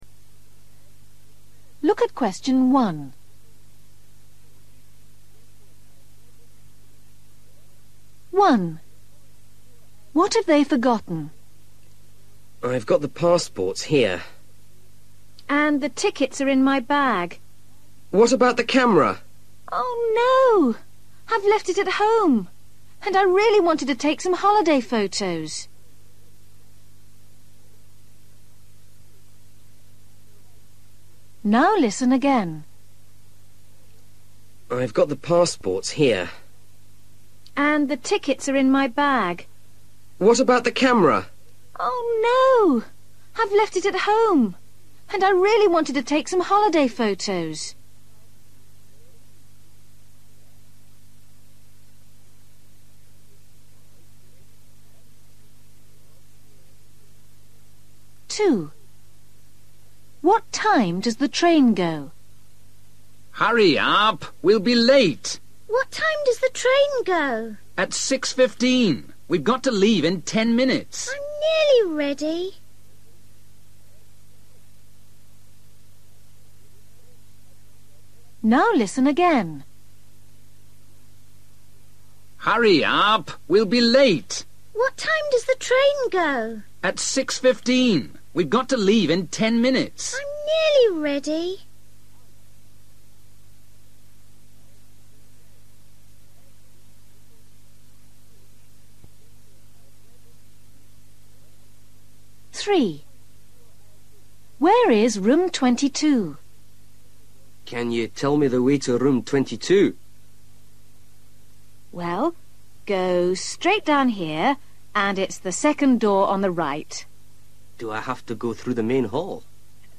Listening: Conversations at home or in a shop